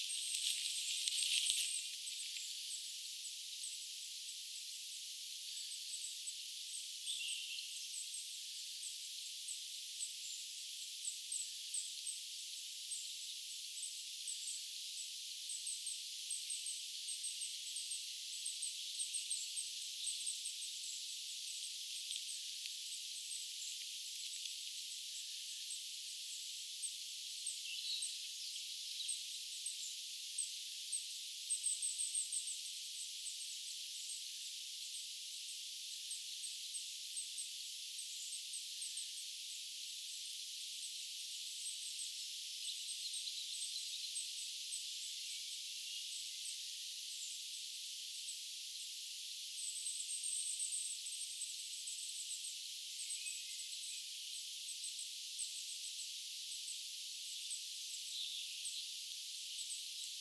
hippiäispesue
ilmeisestikin_hippiaispesue.mp3